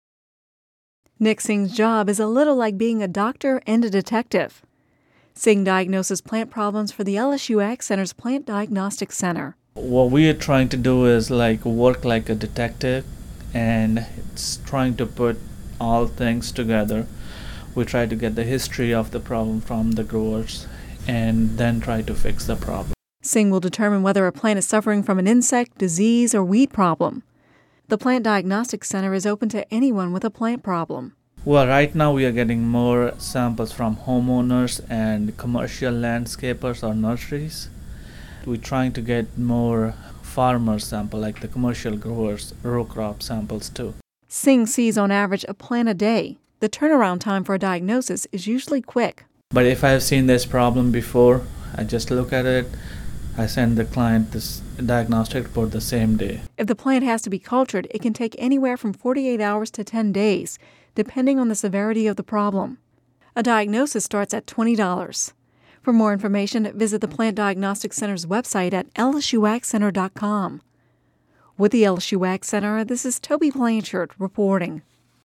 Radio News 03/21/11